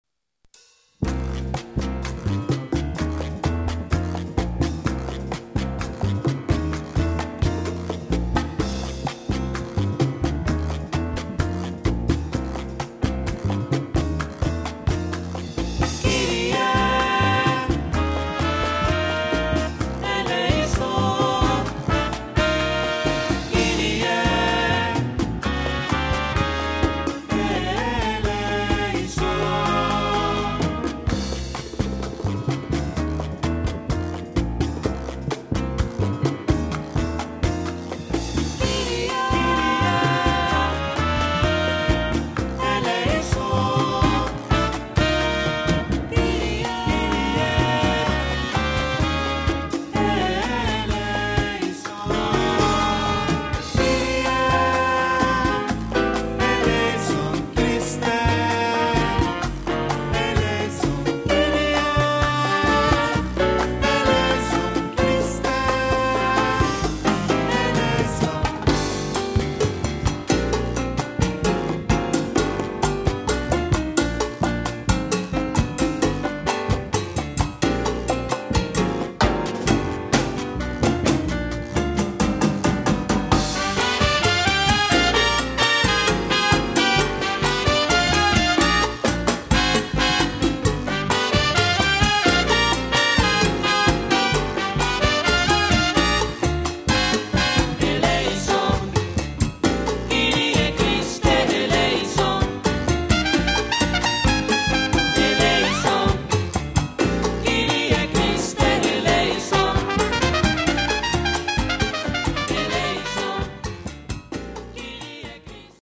Category: Combo Style: Cha Cha